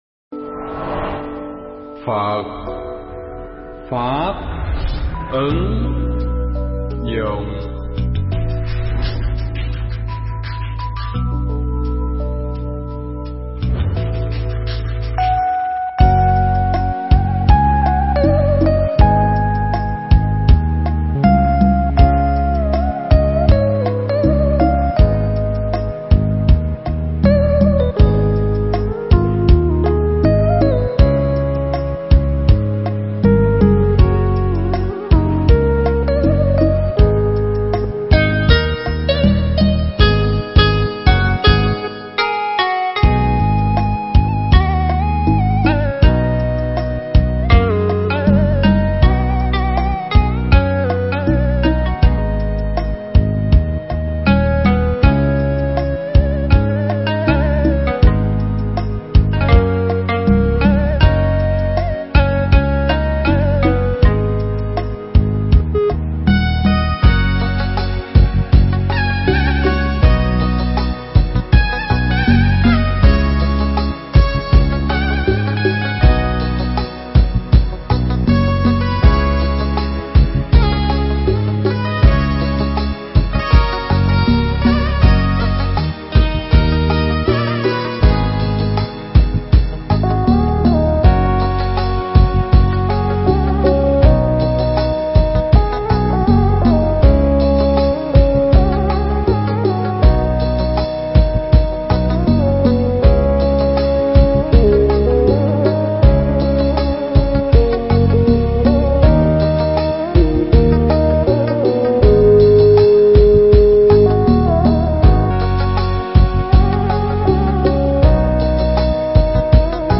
Thuyết Giảng
thuyết giảng tại Thụy Điển